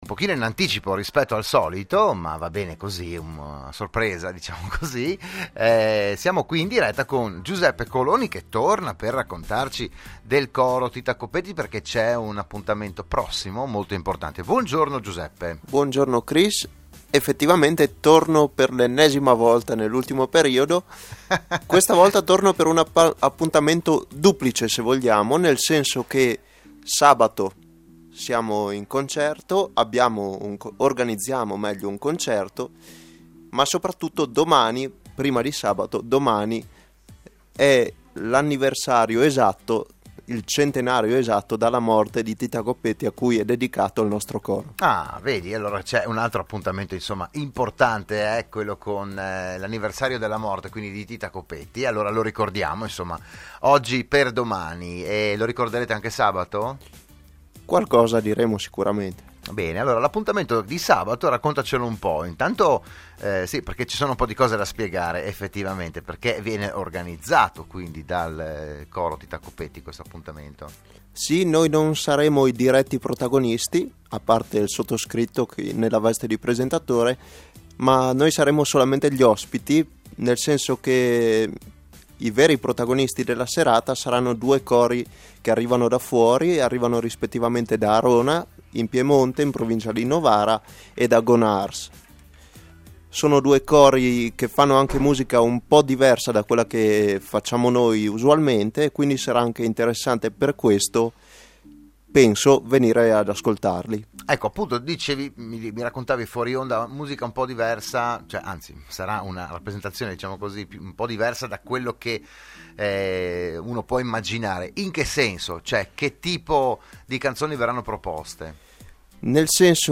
Il PODCAST dell'intervento